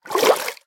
assets / minecraft / sounds / liquid / swim3.ogg
swim3.ogg